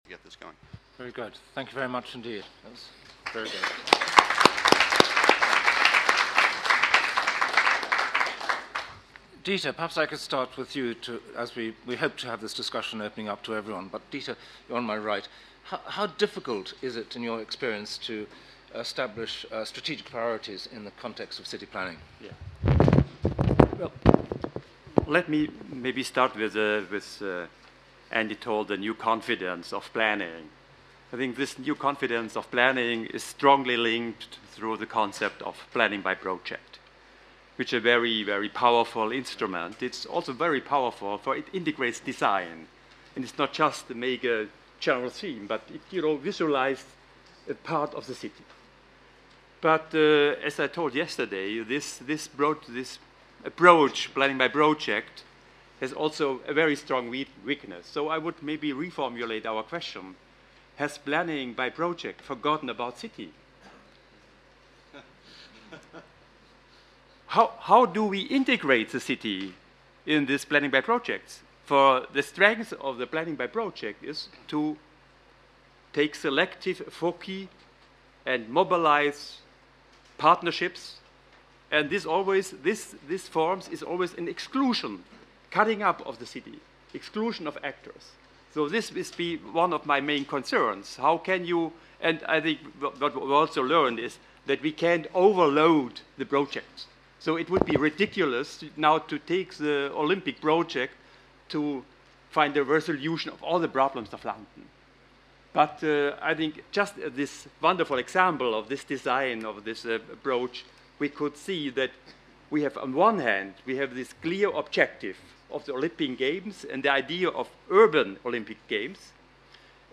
07.03-Panel-discussion-Has-planning-forgotten-about-design-.mp3